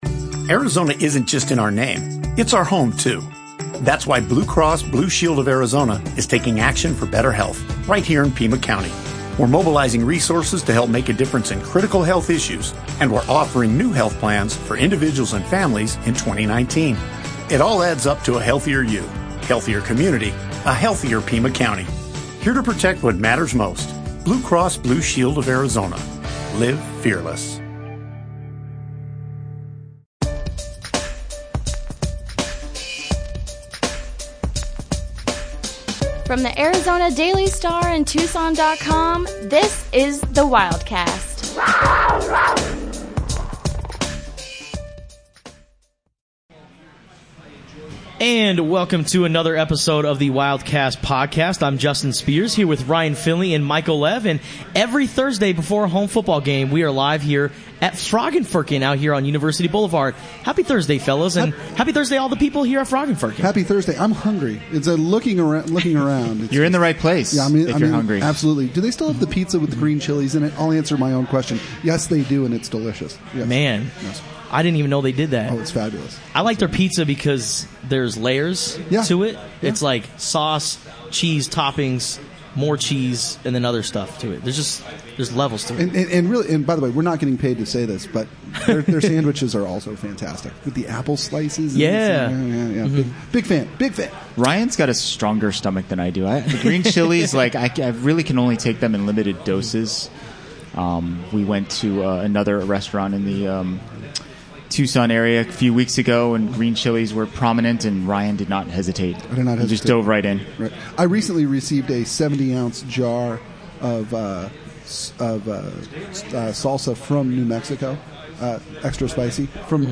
The Wildcast is celebrating its one-year anniversary. This is the fourth in a series of live recorded podcasts at Frog & Firkin every Thursday before Wildcats home games